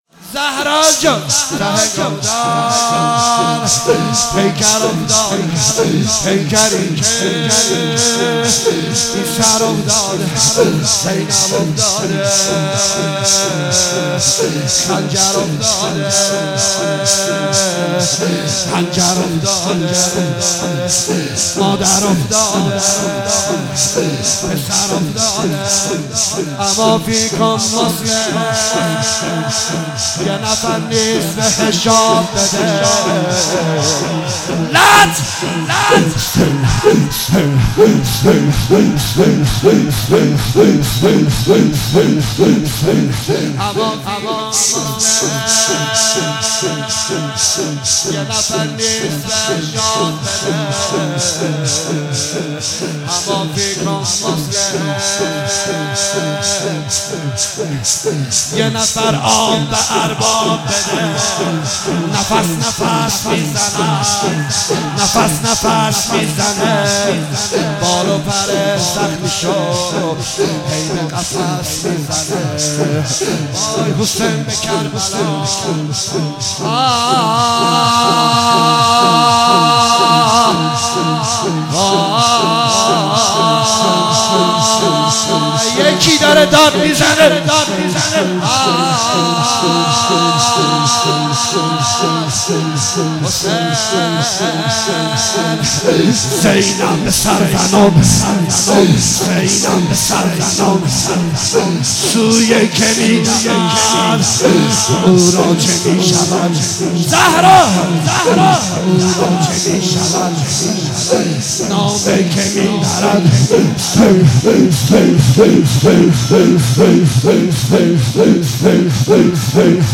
30 تیر 96 - هیئت رزمندگان - شور - یه نفر نیست بهش آب بده